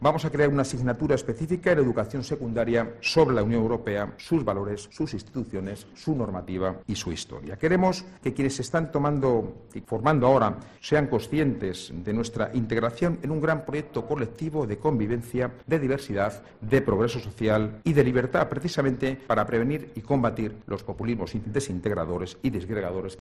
Son las palabras del presidente regional durante el desayuno informativo 'Nueva Economía', en el que también ha habido anuncios como la creación de una nueva asignatura sobre Europa para Secundaria.